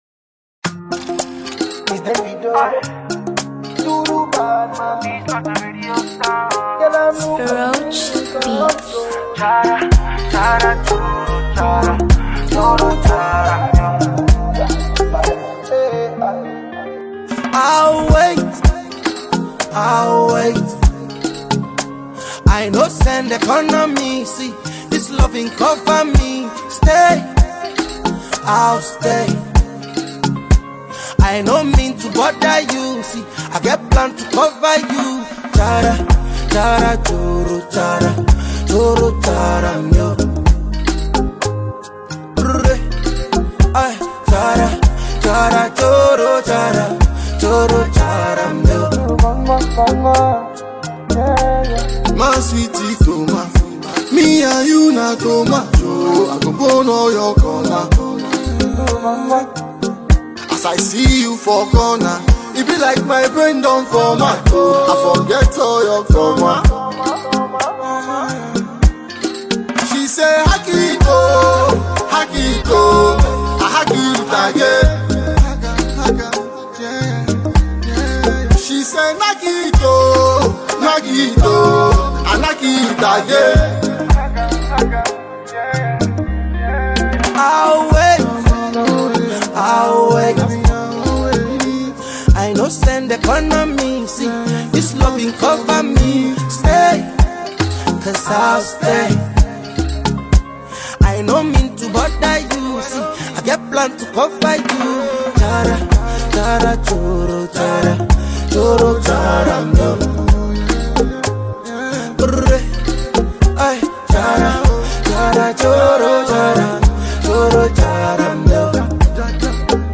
catchy single